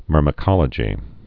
(mûrmĭ-kŏlə-jē)